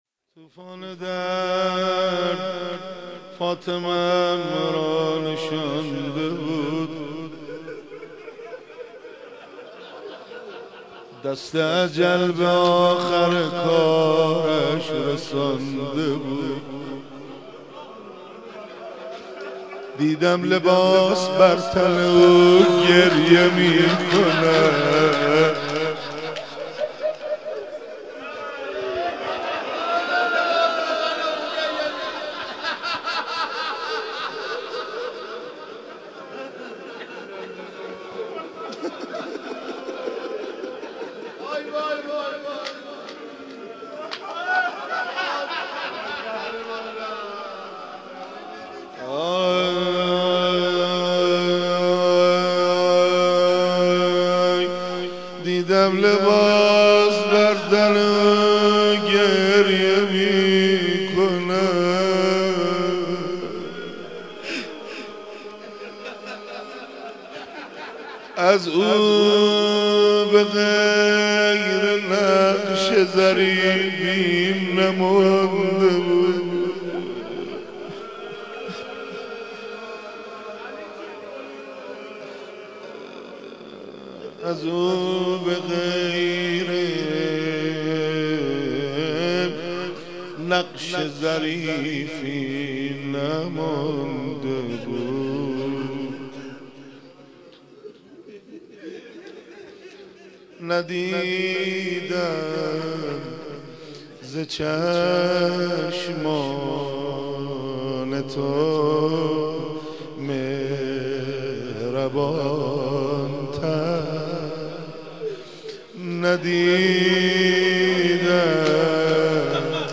ذکر مصیبت شهادت حضرت زهرا(س